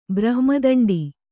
♪ brahma daṇḍi